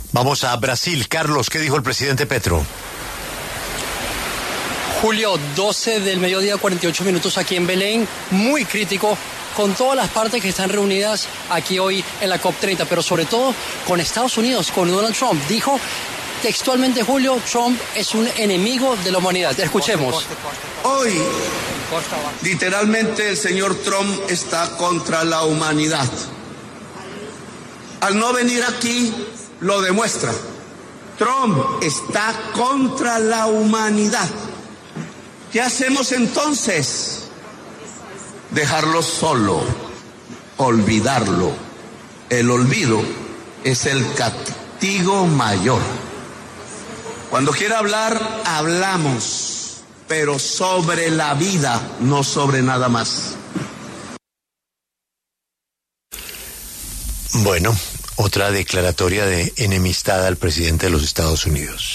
El presidente de la República, Gustavo Petro, habló desde la COP30 que se desarrolla en Belém, Brasil, y arremetió contra su homólogo de Estados Unidos, Donald Trump, a quien calificó como “enemigo de la humanidad” por no asistir al evento que busca combatir el cambio climático.
Escuche esta parte de la intervención del presidente Gustavo Petro aquí: